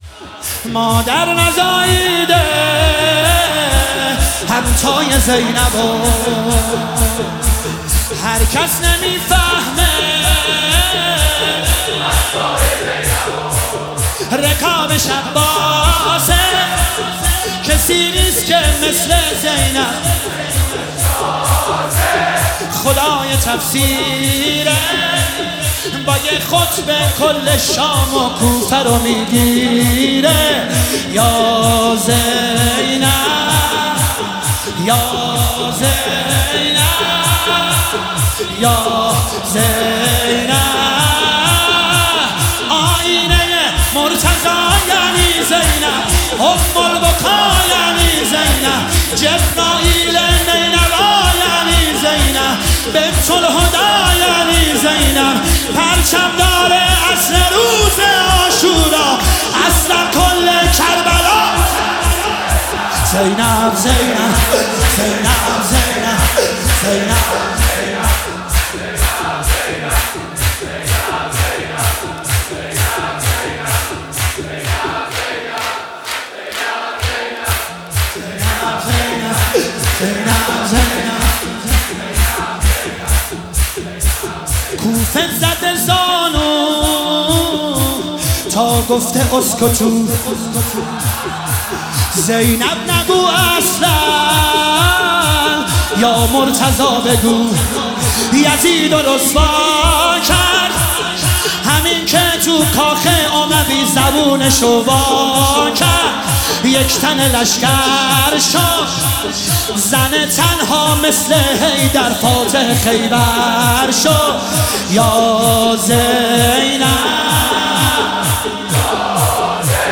مداحی شور طوفانی